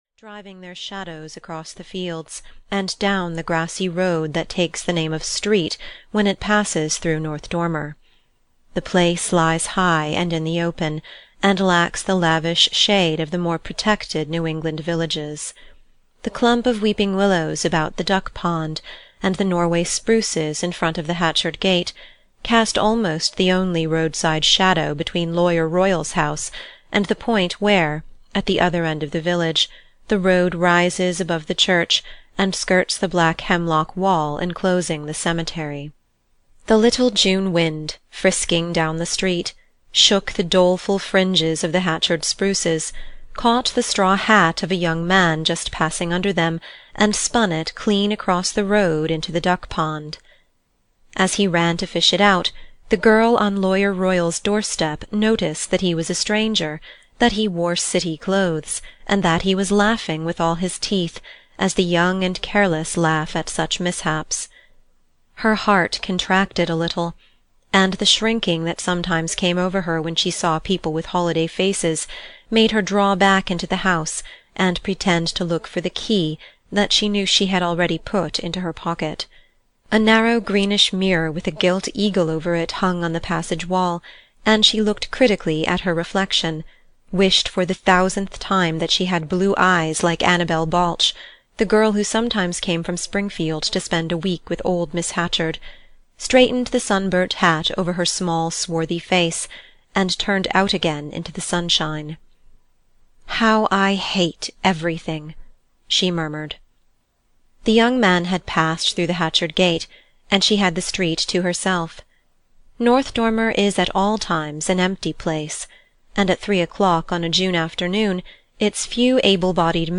Summer (EN) audiokniha
Ukázka z knihy